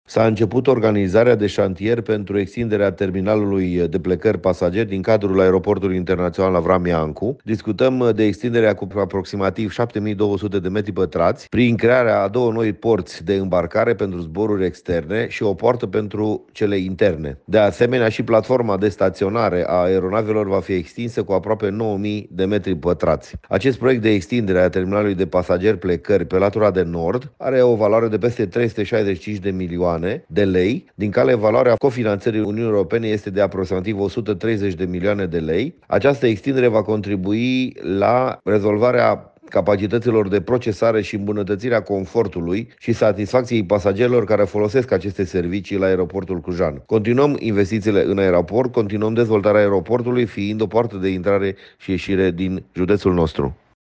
Președintele Consiliului Județean Cluj, Alin Tișe, a anunțat și mărirea suprafeței zonei de staționare a avioanelor, cu aproximativ 9.000 de mp: